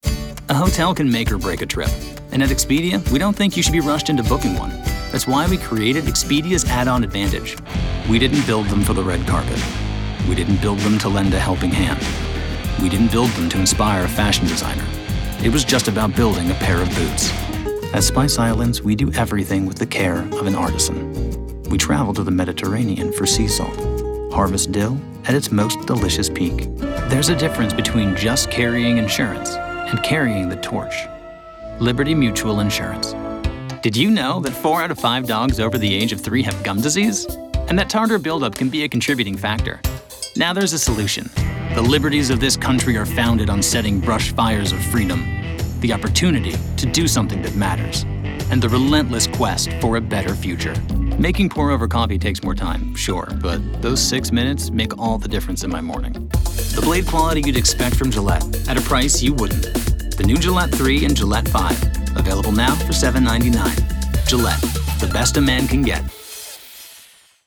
Chicago : Voiceover : Commercial : Men